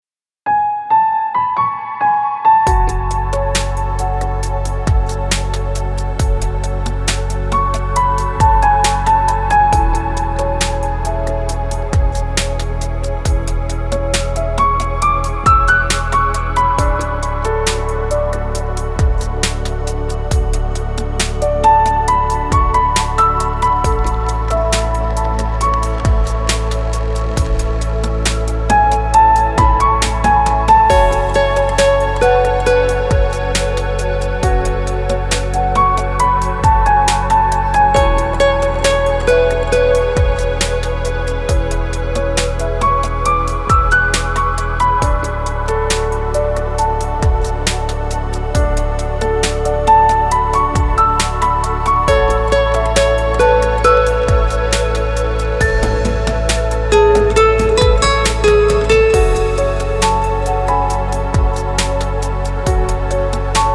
• Качество: 320, Stereo
красивые
без слов
инструментальные
нежные